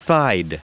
Prononciation du mot thighed en anglais (fichier audio)
Prononciation du mot : thighed